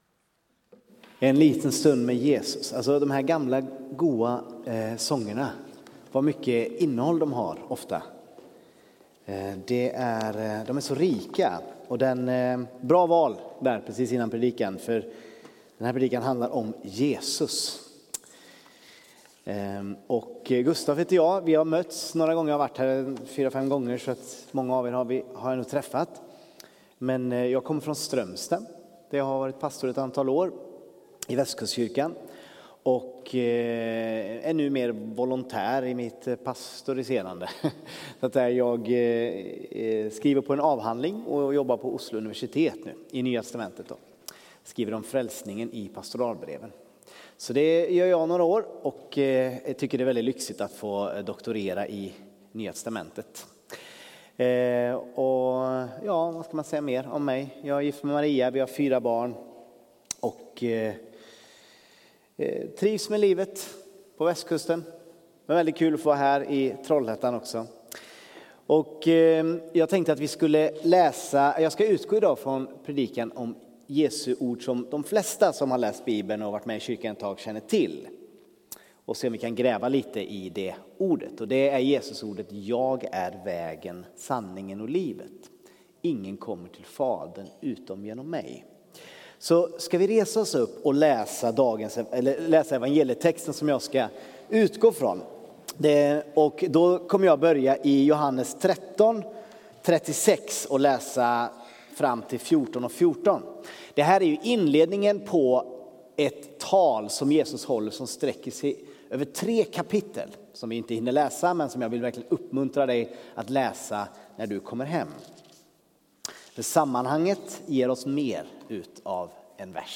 Undervisning från Trollhättan Pingst.